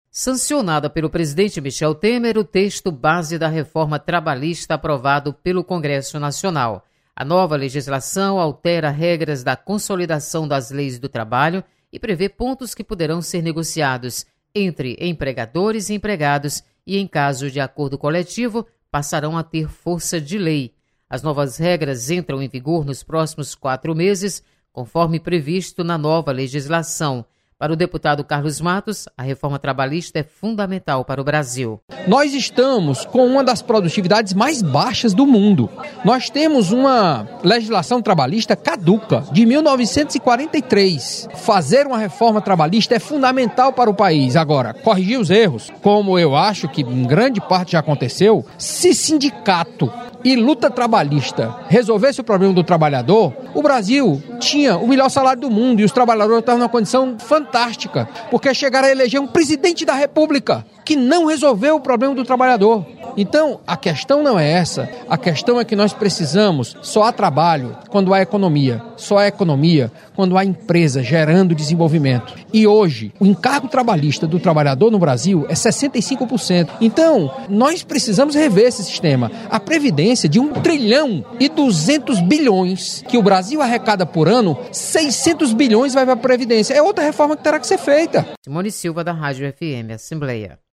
Deputado Carlos Matos analisa texto da Reforma Trabalhista.